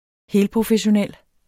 Udtale [ ˈheːl- ]